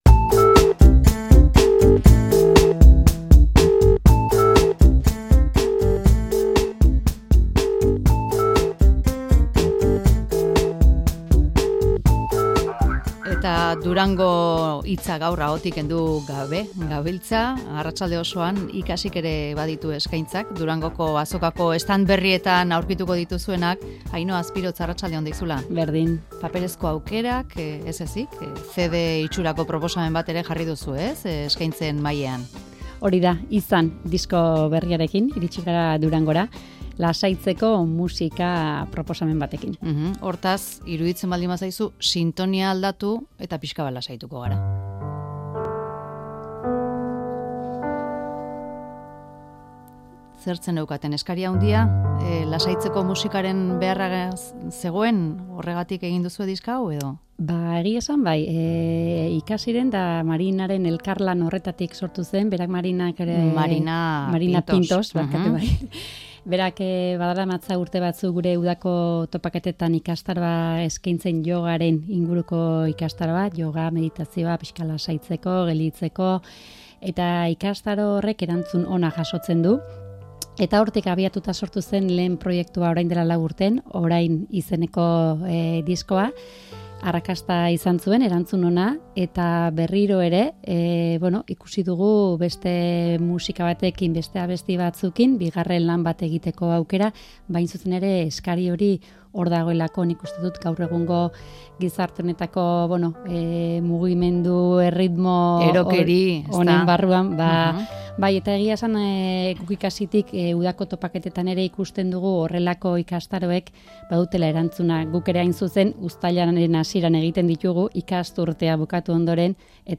lasaitzeko musika